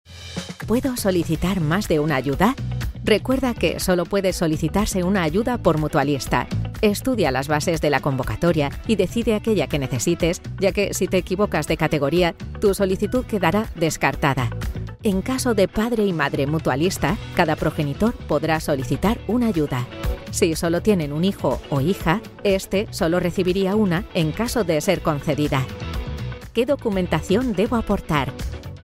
Natürlich, Vielseitig, Tief, Zugänglich, Warm
Unternehmensvideo
Ich biete eine hohe Klangqualität mit schneller Umsetzung und kann meine Stimme Ihrem Projekt anpassen.